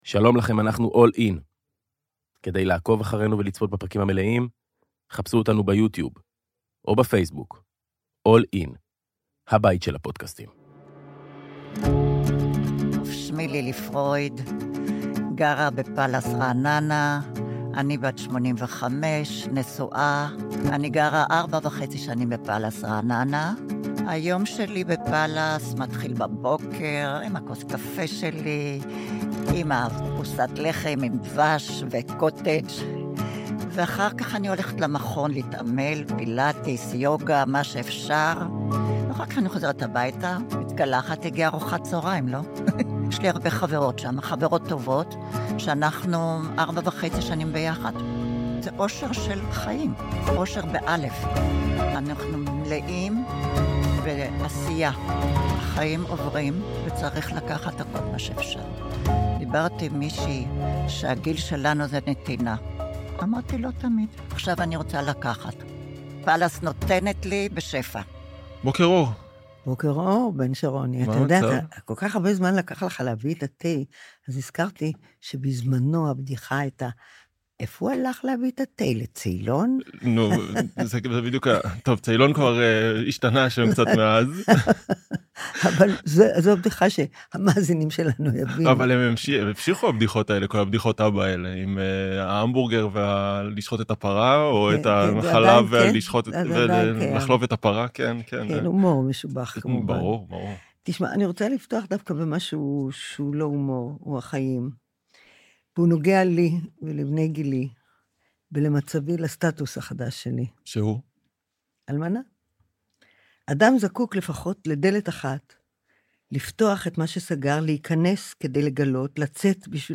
ומחקה לרגע את גולדה ובמלאות לדוד גרוסמן 70 נזכרת ב׳זמן הצהוב׳.